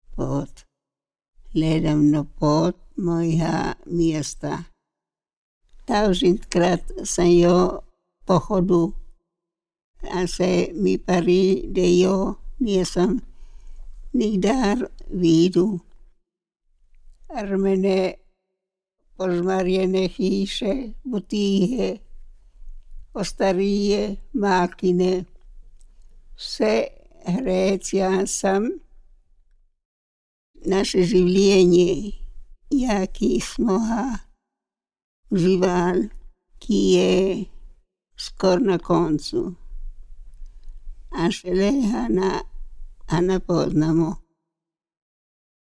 DIALETTO DELLE VALLI DEL NATISONE
Registrazione audio del dialetto delle valli del Natisone, Casa di riposo Giuseppe Sirch di San Pietro al Natisone: